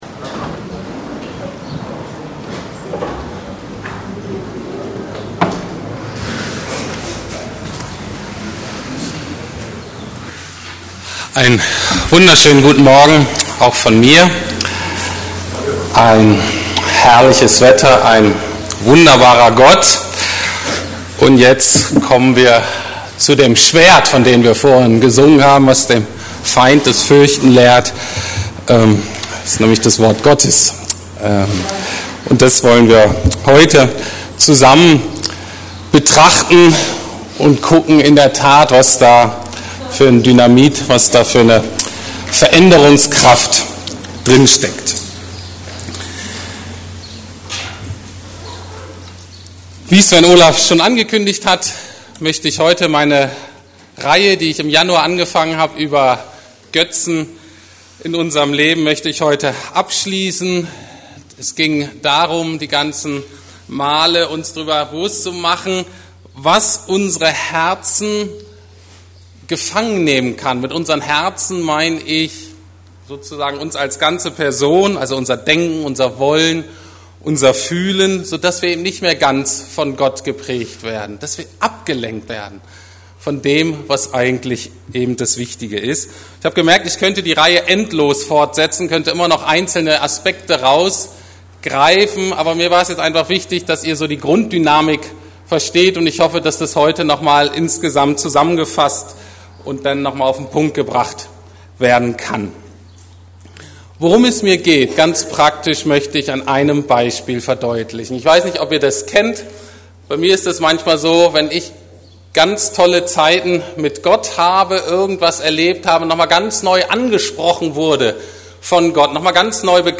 ~ Predigten der LUKAS GEMEINDE Podcast